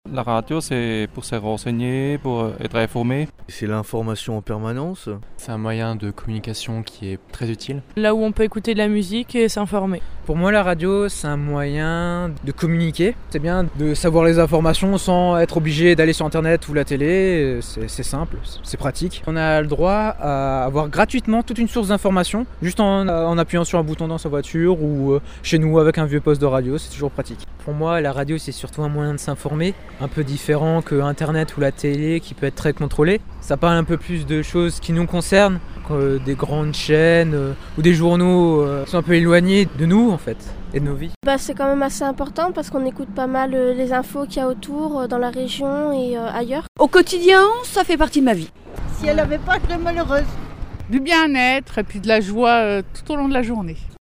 MICRO-TROTTOIR
Plusieurs personnes répondent à la question "micro-trottoir": que représente la radio pour vous?